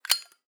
zippo_open_01.wav